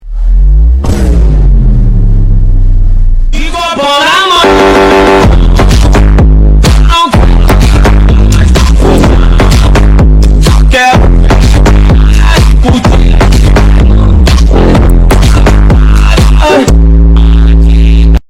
Vine boom H2N1
bass-drop-vine-boom-made-with-voicemod-technology_wiBiw17K.mp3